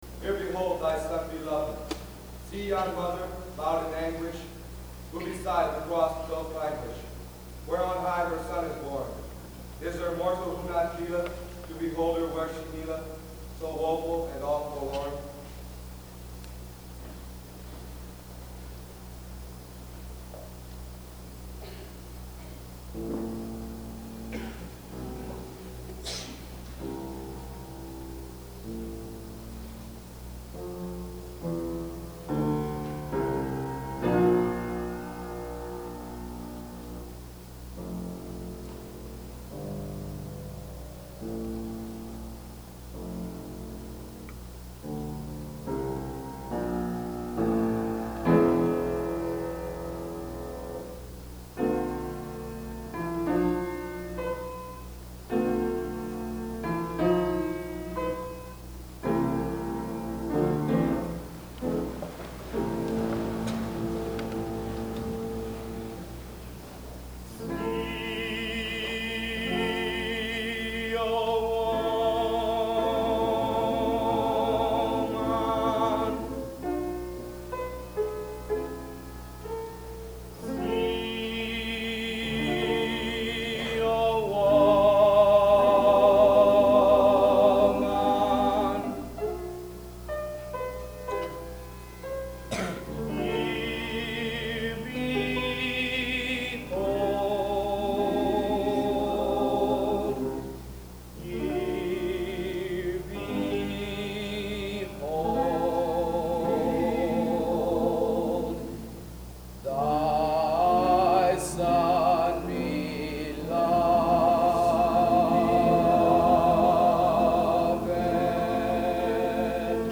Soprano
Baritone